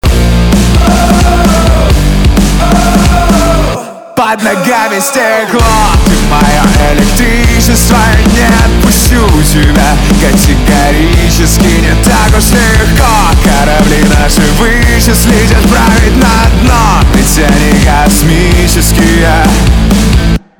русский рок , гитара , барабаны